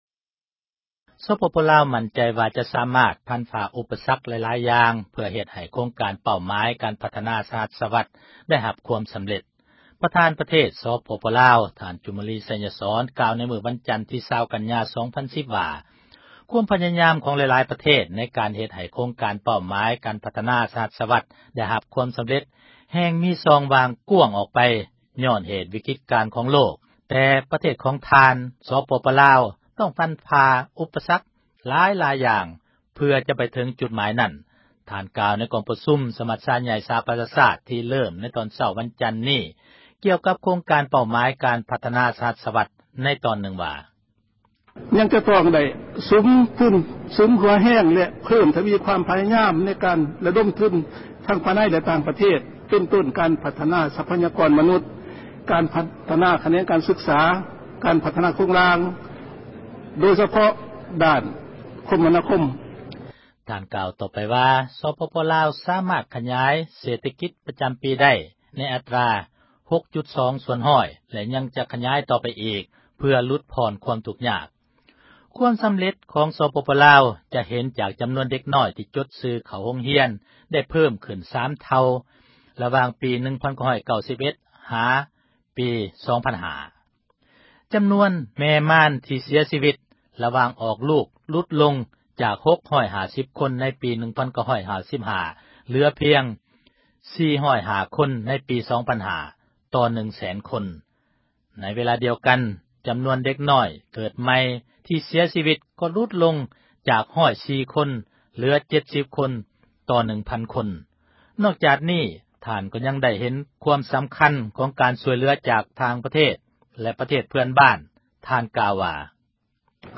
ທ່ານກ່າວ ໃນກອງປະຊຸມ ສະມັດຊາໃຫຍ່ ສະຫະ ປະຊາຊາດ ທີ່ເລີ້ມ ໃນຕອນເຊົ້າ ມື້ວັນຈັນນີ້ ກ່ຽວກັບໂຄງການ ເປົ້າໝາຍການ ພັທນາ ສະຫັດສວັດ ໃນຕອນນຶ່ງວ່າ: